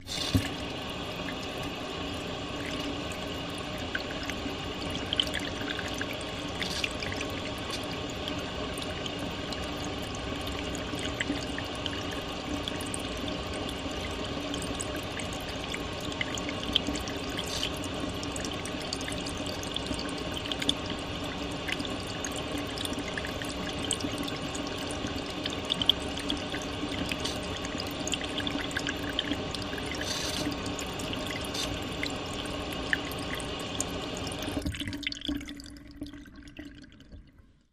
Wash Basin Aluminum